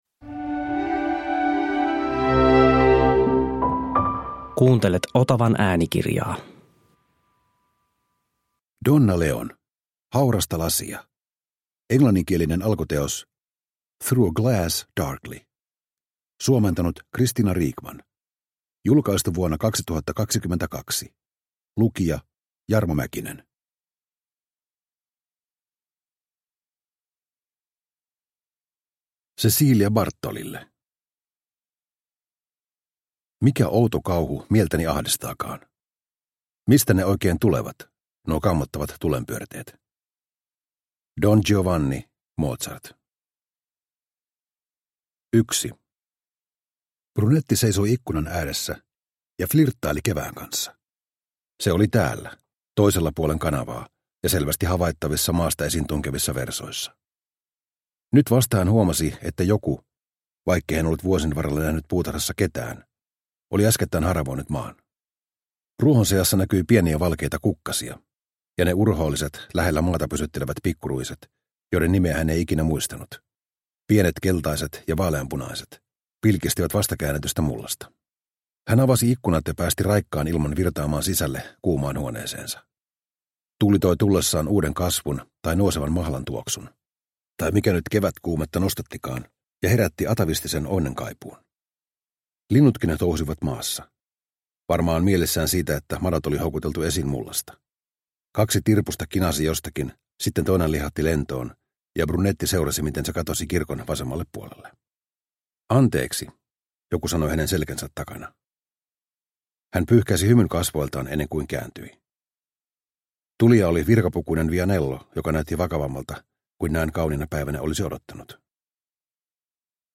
Haurasta lasia – Ljudbok – Laddas ner